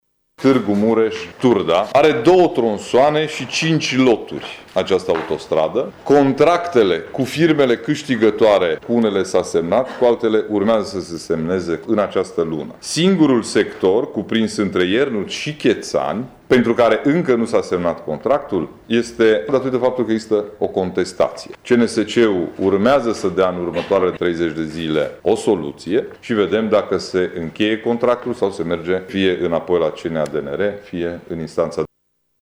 Președintele Consiliului Judetean Mureș, Ciprian Dobre, a precizat, astăzi, într-o conferință de presă, că valoarea celor doua investiții este de aproape 1,8 miliarde de euro.